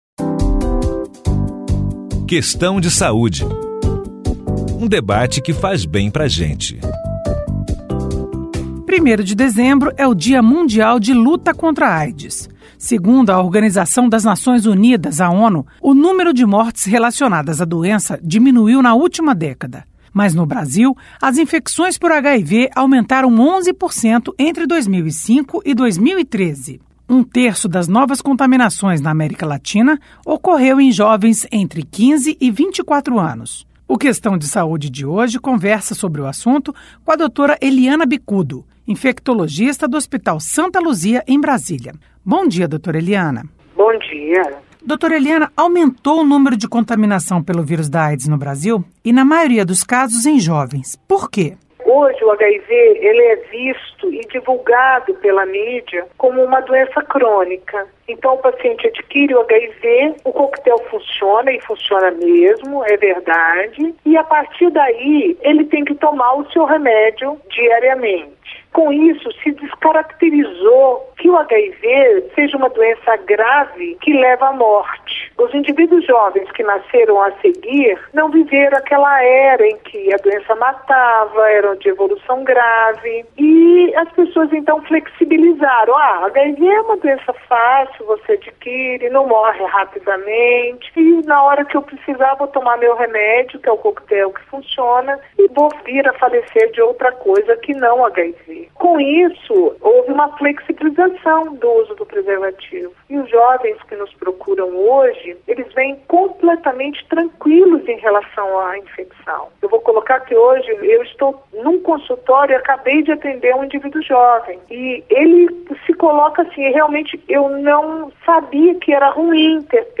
Entrevista com a médica infectologista